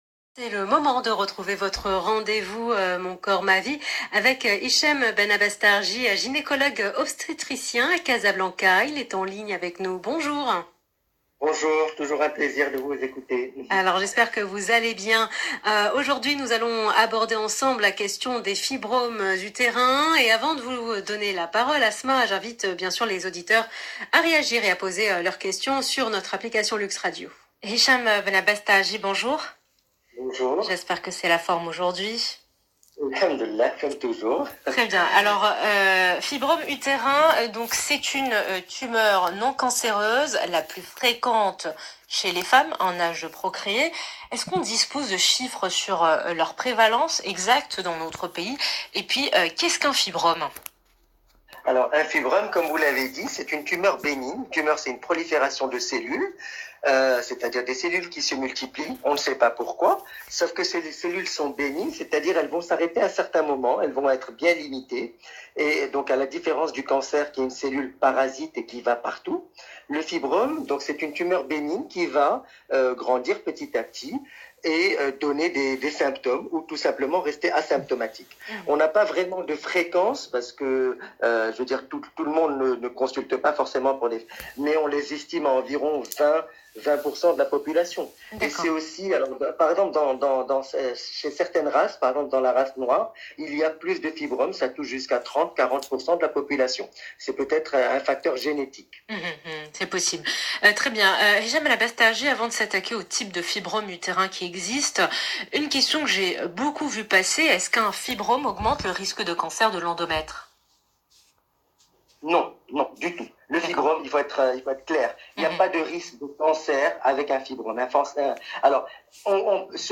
Essai de réponse au cours de cette interview dans l’Heure essentielle sur LUXE RADIO